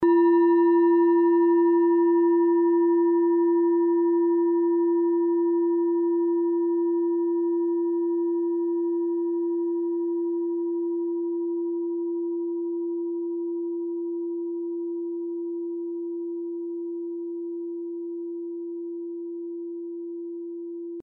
Kleine Klangschale Nr.28
Klangschale-Gewicht: 470g
Klangschale-Durchmesser: 13,6cm
Sie ist neu und ist gezielt nach altem 7-Metalle-Rezept in Handarbeit gezogen und gehämmert worden.
(Ermittelt mit dem Minifilzklöppel)
Der Chironton liegt bei 171,80 Hz und ist die 38. Oktave der Umlauffrequenz des Chirons um die Sonne.
In unserer Tonleiter liegt dieser Ton nahe beim "F".
kleine-klangschale-28.mp3